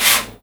R - Foley 91.wav